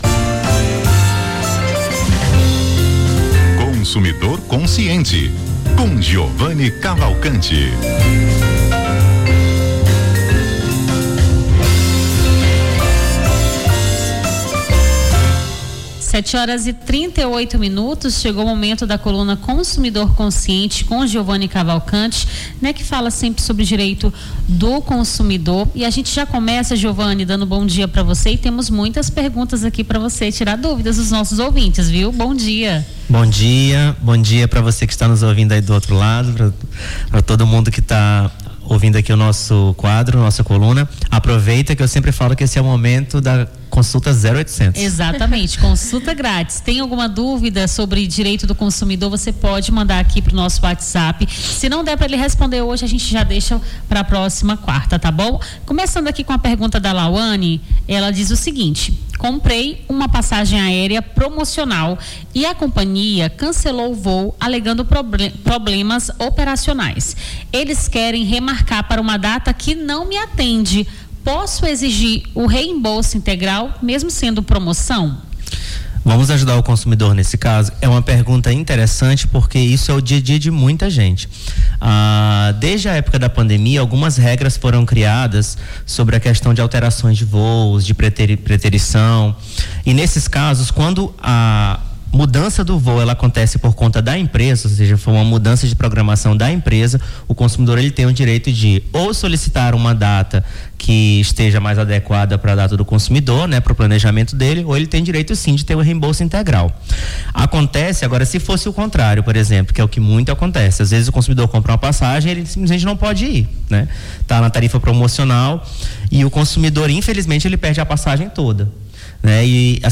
Consumidor Consciente: advogado tira-dúvidas dos ouvintes sobre direito do consumidor